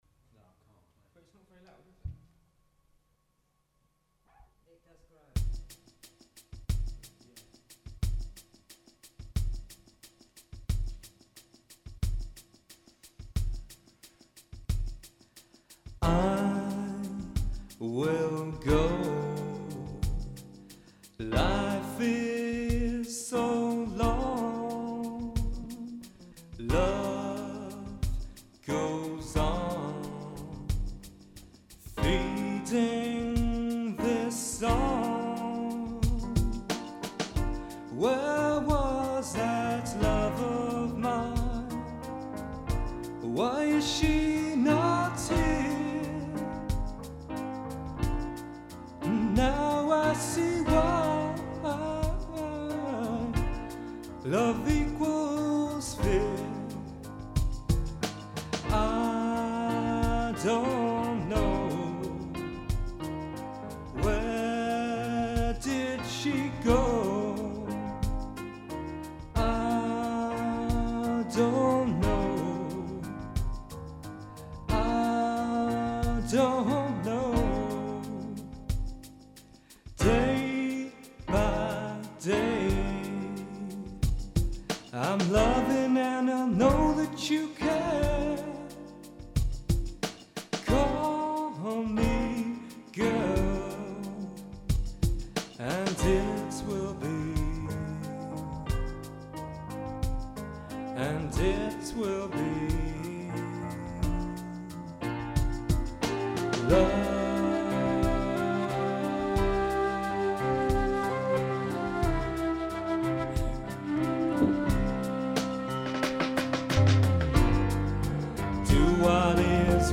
(live)
keyboards and harmony vocals
bass and lead vocals
flute and harmony vocals